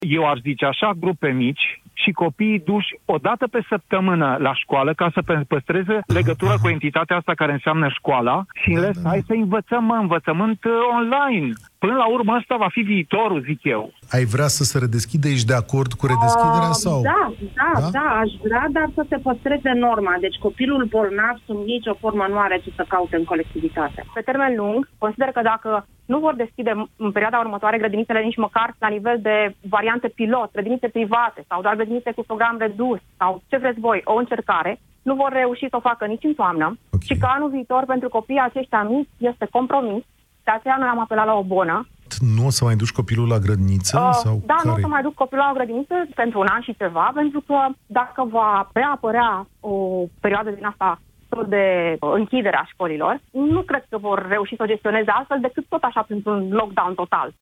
28mai-16-Parinti-despre-solutii-gradinita.mp3